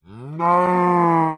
snapshot / assets / minecraft / sounds / mob / cow / say3.ogg